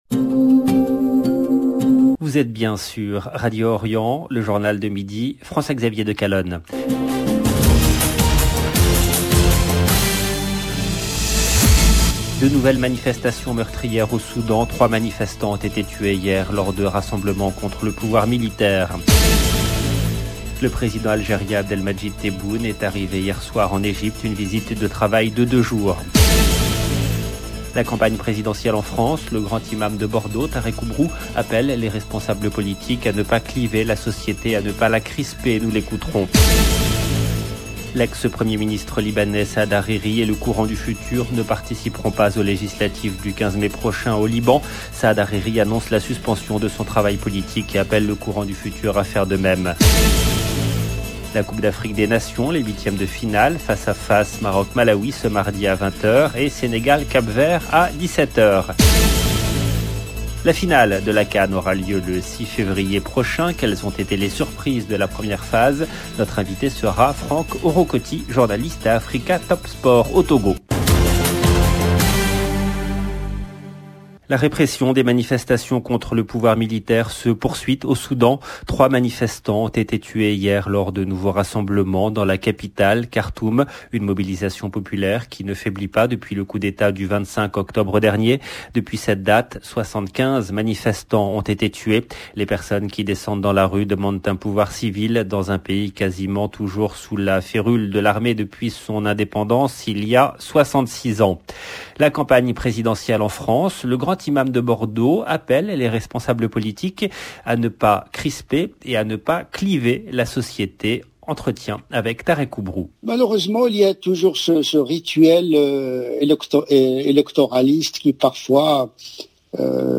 LE JOURNAL DE MIDI EN LANGUE FRANCAISE DU 25/01/22 LB JOURNAL EN LANGUE FRANÇAISE